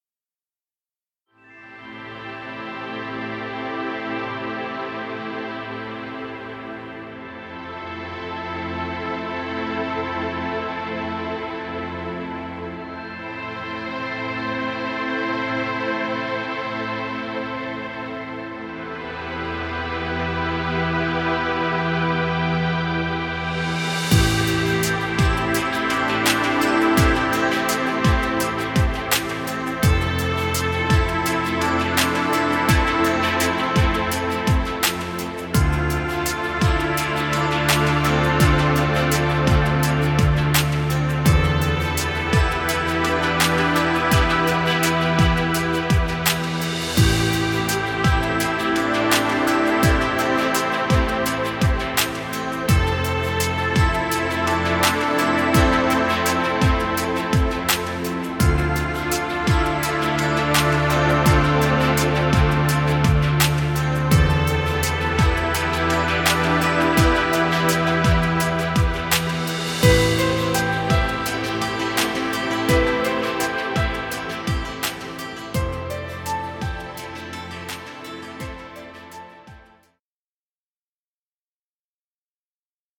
Relaxing music.